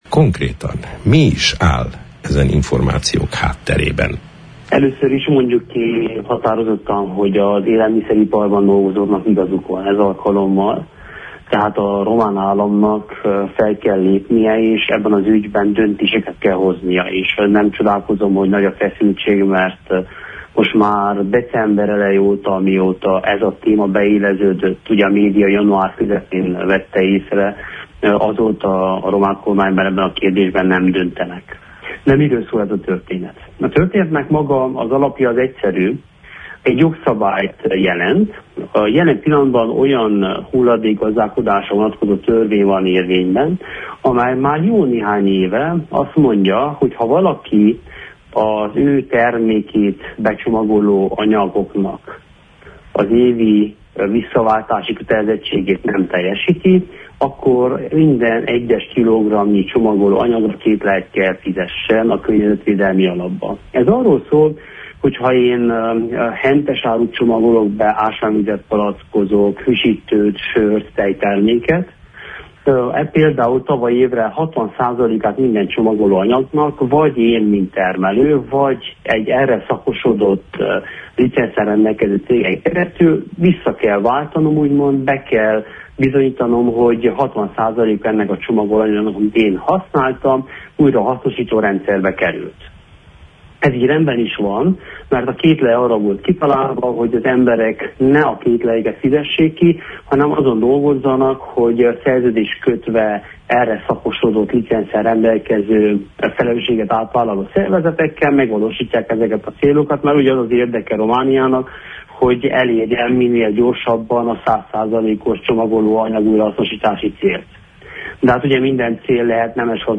Korodi Attila Hargita megyei parlamenti képviselővel, korábbi környezetvédelmi miniszter beszélt a kedd délutáni Naprakész műsorban